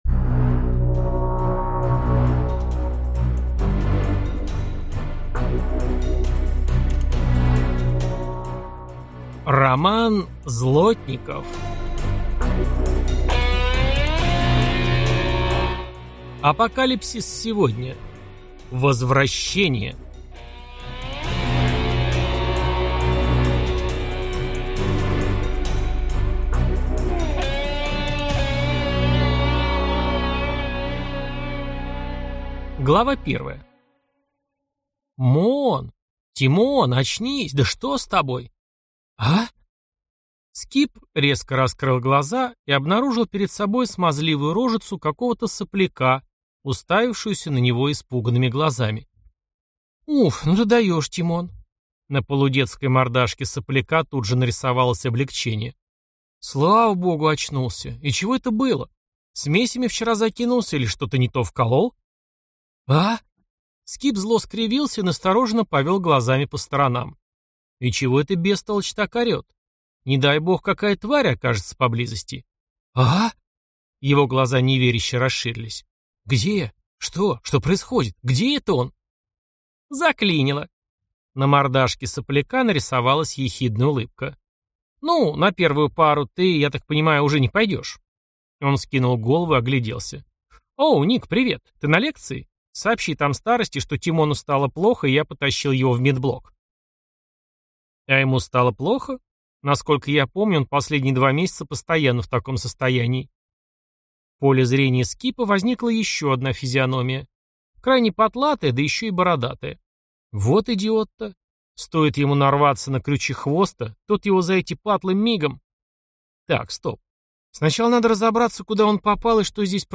Аудиокнига Апокалипсис сегодня. Возвращение | Библиотека аудиокниг